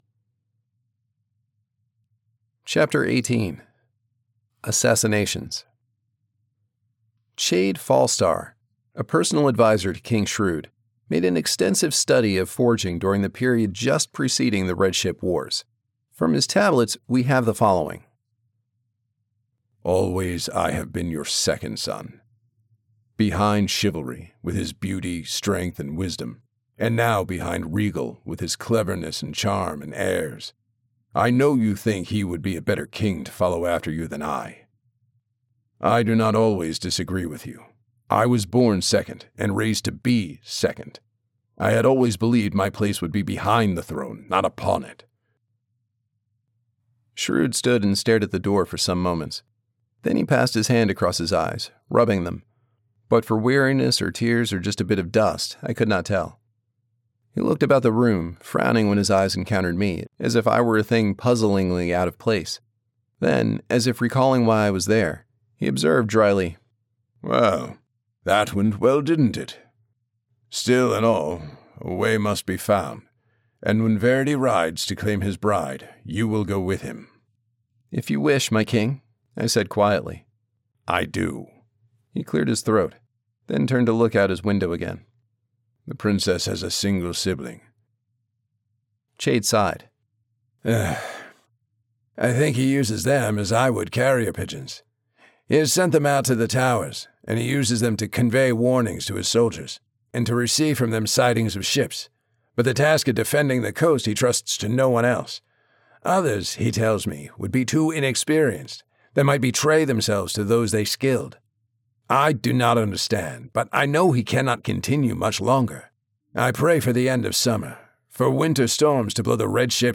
Articulate, intelligent, conversational. Your favorite, laid-back college professor.
Audiobook Samples
US Midatlantic, US Southeast, US Northeast
Middle Aged
Audiobook Sample - Assassins Apprentice.mp3